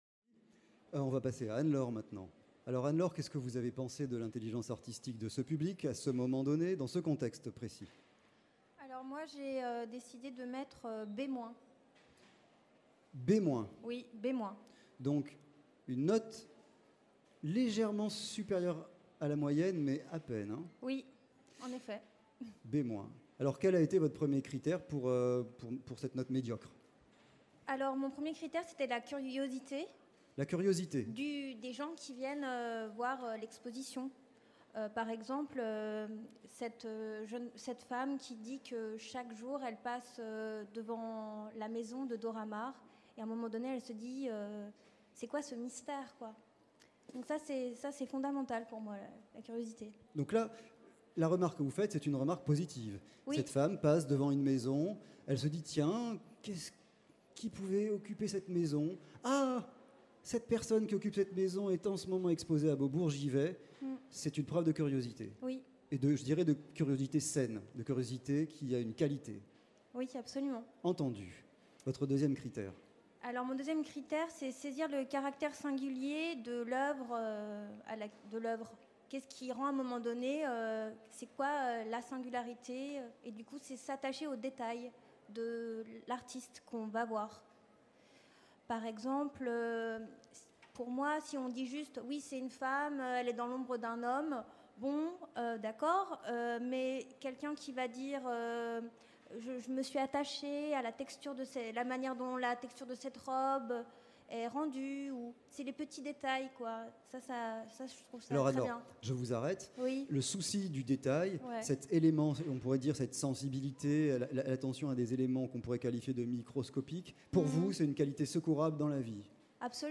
Centre Pompidou Festival EXTRA Paris, La Gaîté Lyrique, La Maison de la Poésie, ArteC Université Paris 8